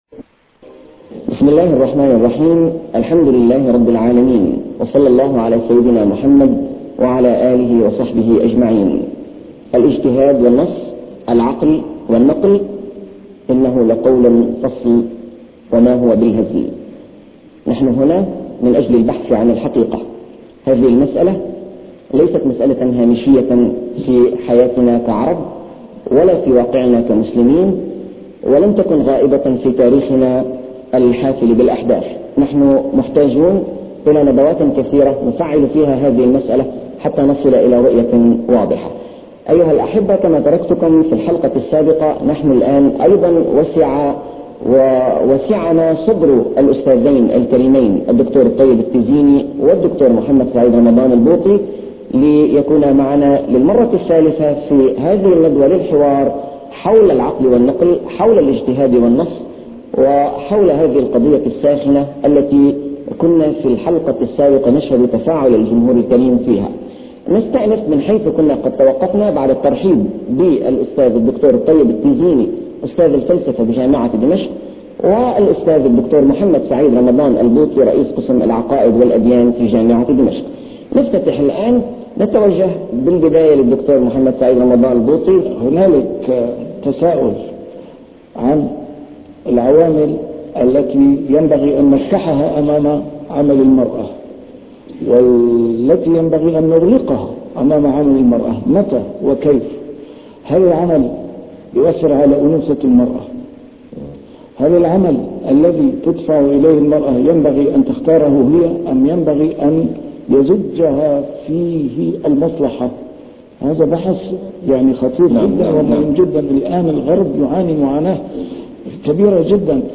مناظرة قديمة مع د. الطيب التيزيني (الحلقة الثالثة)